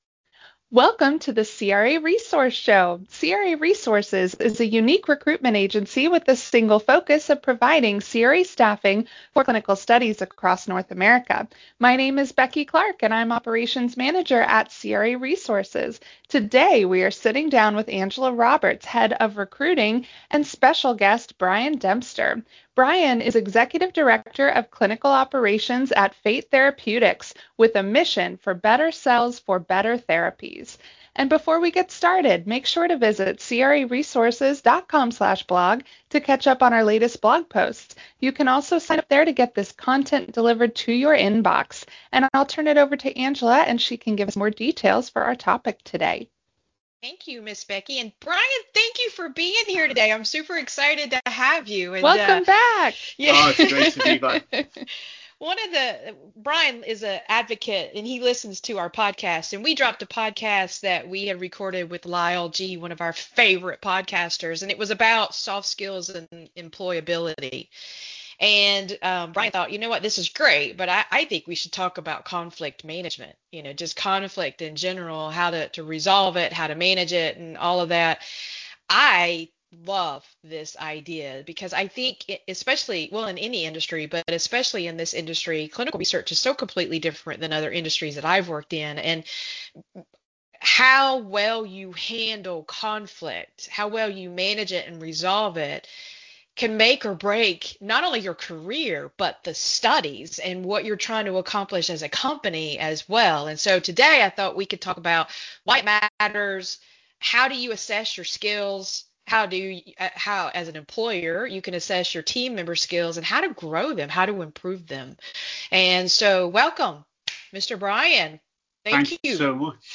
Our conversation delves into why conflict resolution strategies involve much more than simply avoiding disagreements.